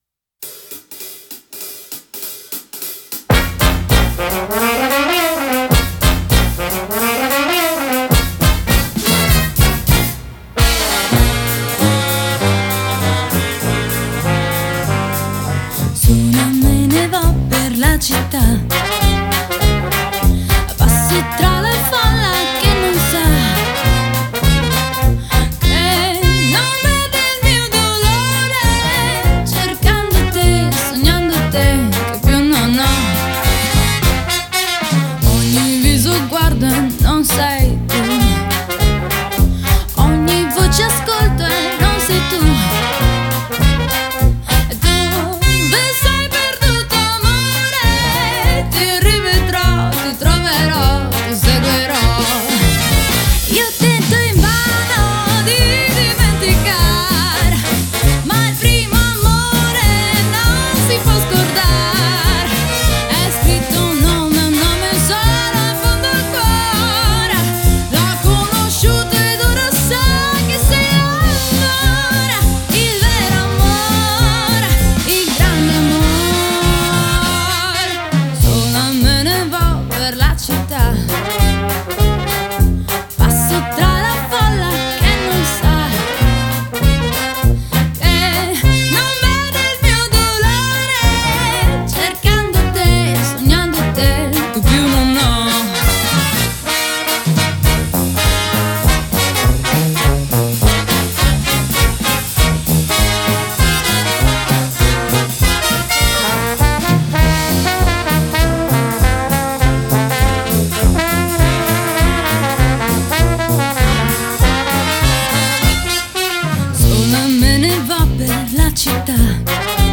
Genre: Swing, Jazz